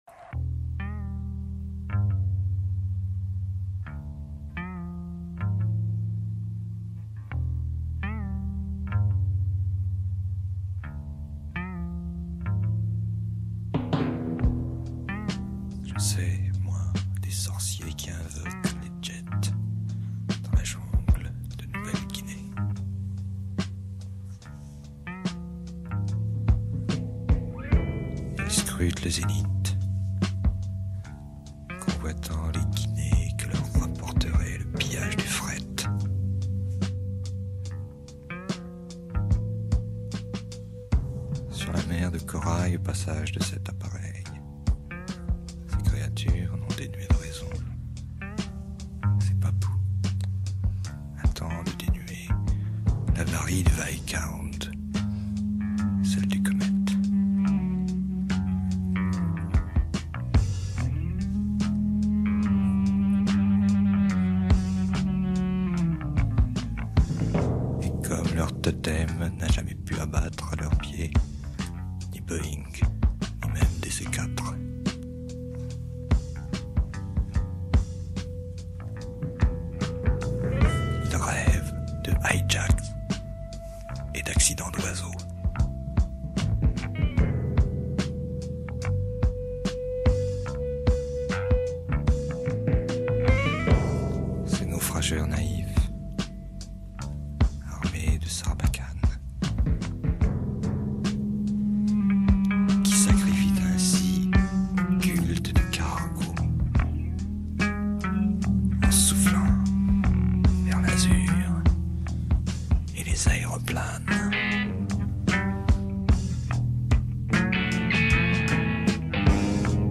Reprenant la trame « prog rock » de l’album